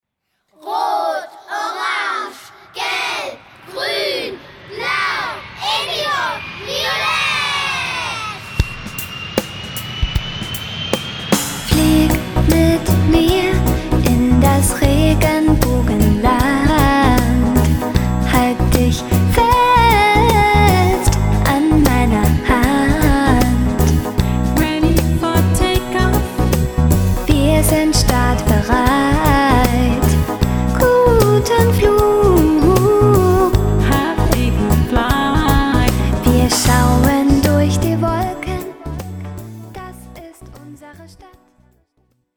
Personalisierte Kinderlieder für den ganzen Tag.